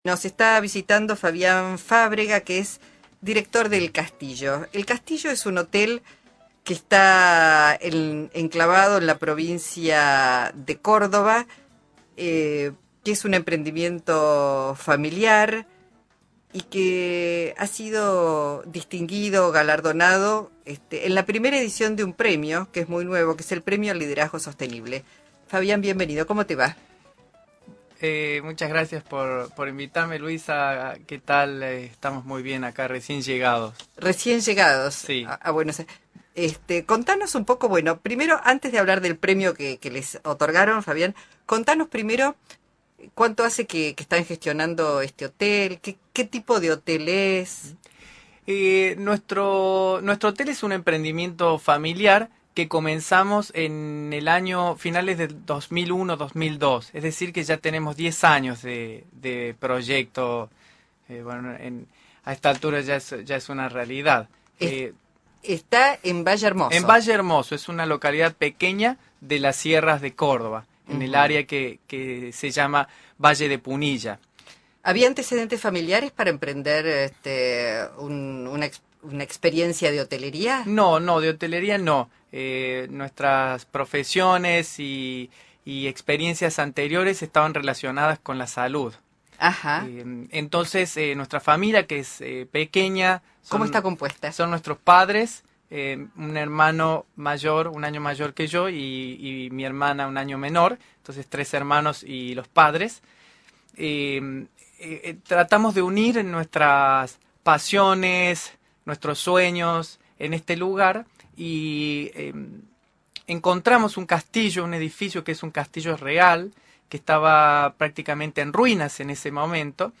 Entrevista
Entrevista a uno de nuestros directores en Radio Cooperativa AM770